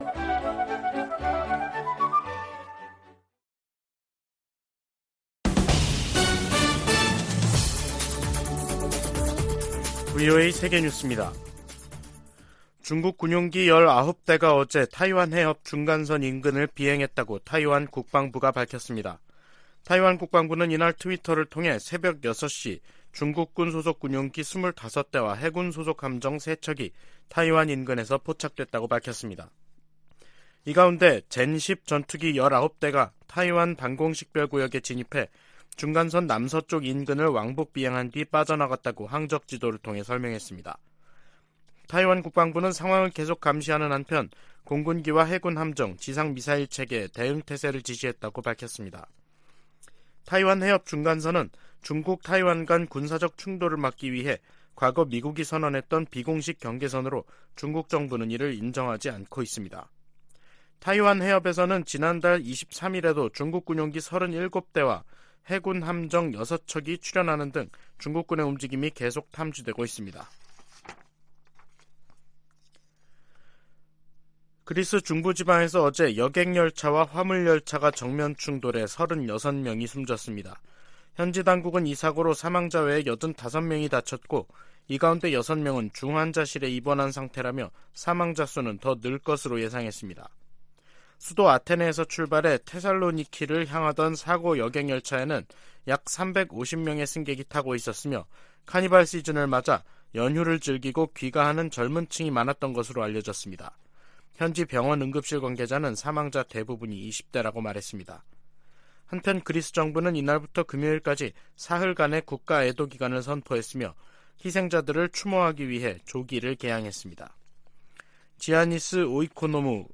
VOA 한국어 간판 뉴스 프로그램 '뉴스 투데이', 2023년 3월 1일 2부 방송입니다. 미국과 한국은 확장억제수단 운용연습(DSC TTX)을 성공적으로 진행했다며, 대북 확장억제 강화 필요성에 동의했다고 미 국방부가 밝혔습니다. 유엔 군축회의에서 한국 등이 북한의 핵과 탄도미사일 개발을 심각한 안보 도전이라고 비판했습니다. 윤석열 한국 대통령은 3.1절 기념사에서 일본은 협력 파트너로 변했다며 북 핵 대응 미한일 공조의 중요성을 강조했습니다.